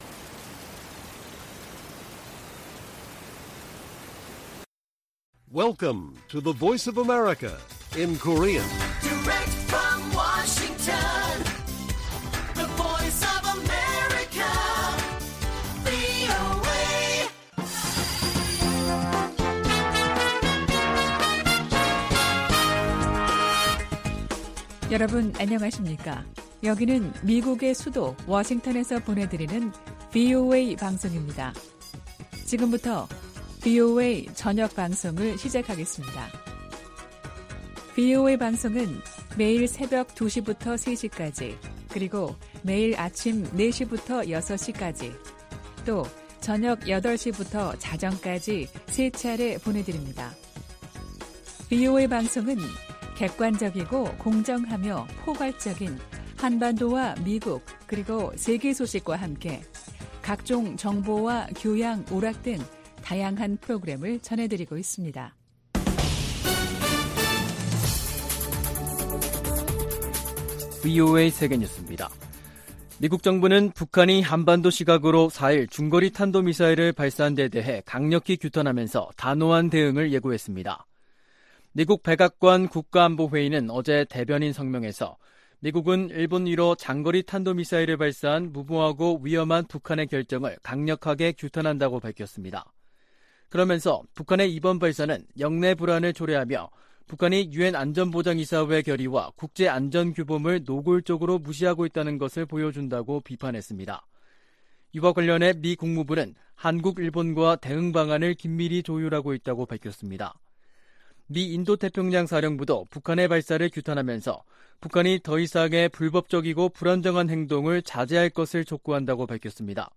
VOA 한국어 간판 뉴스 프로그램 '뉴스 투데이', 2022년 10월 4일 1부 방송입니다. 북한이 4일 일본열도를 넘어가는 중거리 탄도미사일(IRBM)을 발사했습니다.